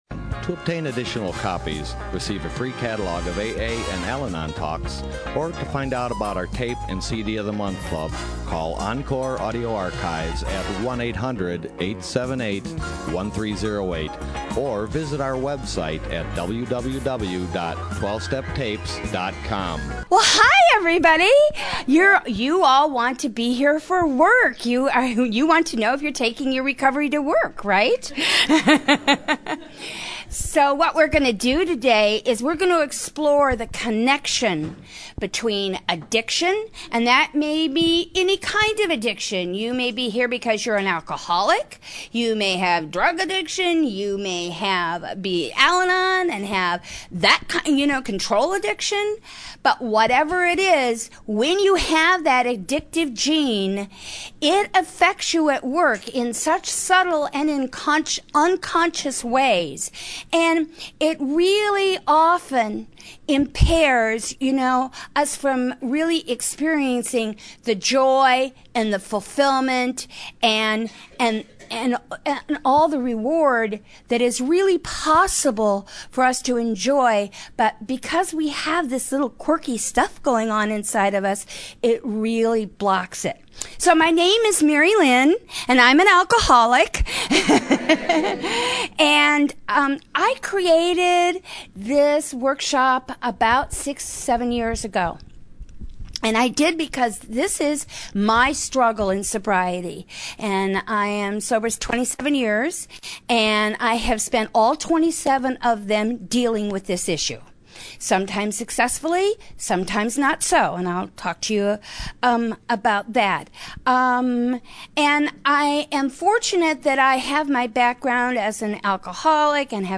SOUTHBAY ROUNDUP 2014